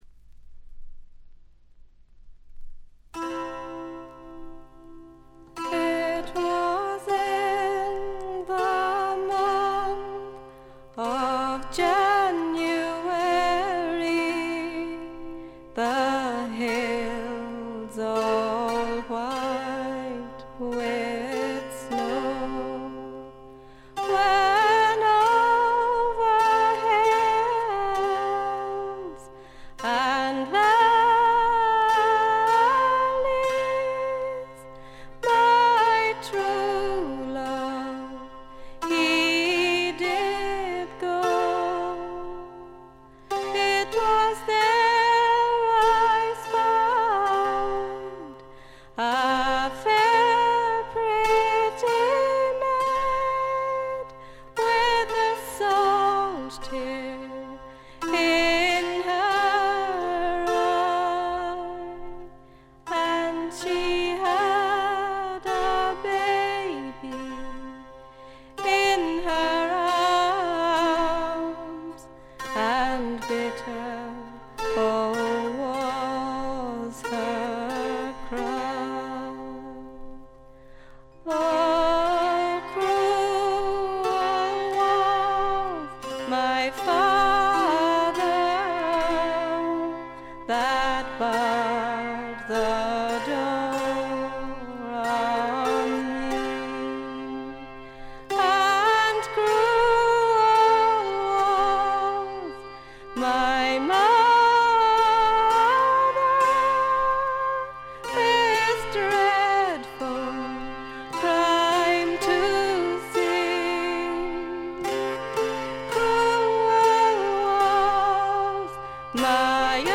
わずかなチリプチ程度。
試聴曲は現品からの取り込み音源です。
Arranged By - Woods Band　Written-By - Trad.
Recorded September 1971, Morgan Studio 2.